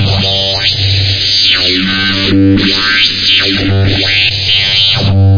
描述：用短元音摇摆的低音来建立dubstep的低音线
Tag: 89 bpm Dubstep Loops Bass Wobble Loops 231.79 KB wav Key : Unknown